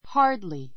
hardly A2 hɑ́ː r dli ハ ー ド り 副詞 ほとんど～ない I could hardly sleep last night.